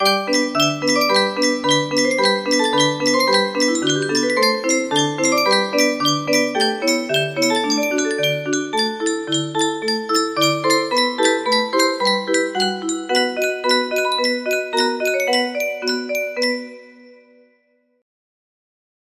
Clone of Toreador March music box melody